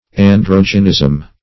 Search Result for " androgynism" : The Collaborative International Dictionary of English v.0.48: Androgyny \An*drog"y*ny\, Androgynism \An*drog"y*nism\, n. Union of both sexes in one individual; hermaphroditism.